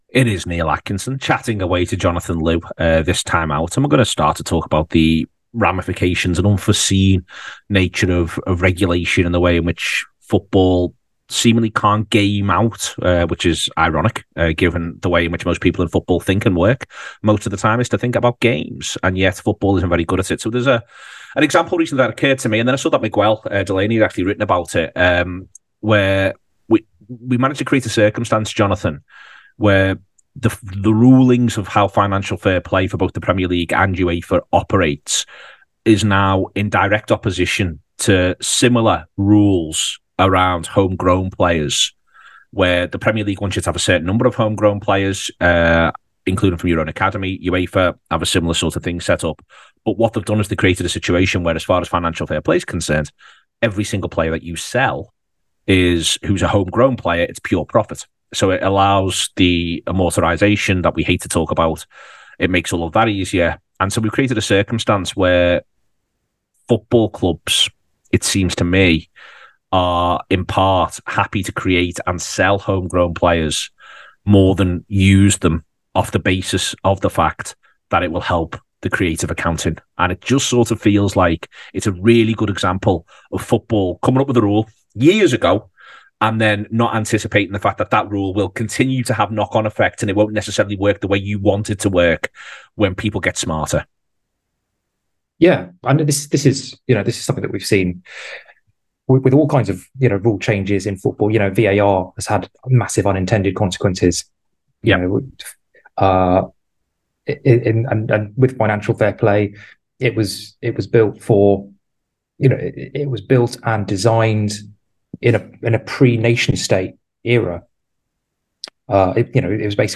Below is a clip from the show – subscribe for more discussion around football regulation…